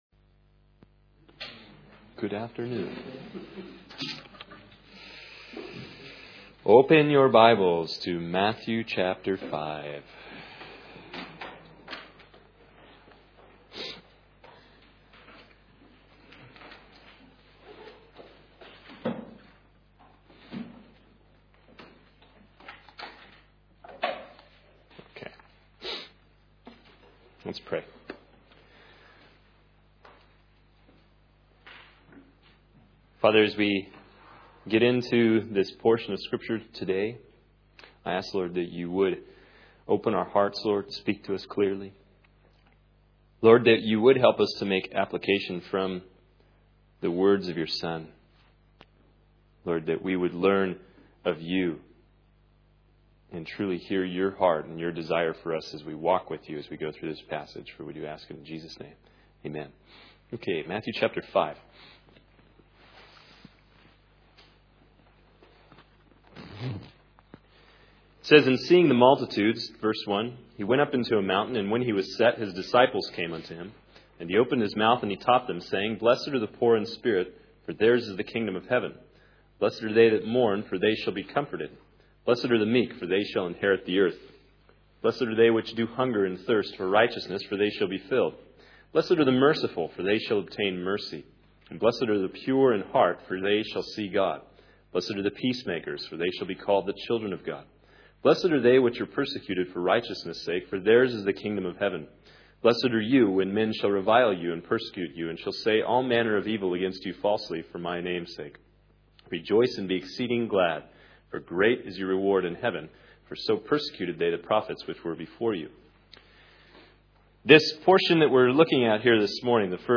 In this sermon, the speaker begins by praying for understanding and guidance as they delve into Matthew chapter 5. They focus on the Beatitudes, starting with 'Blessed are the poor in spirit, for theirs is the kingdom of heaven.'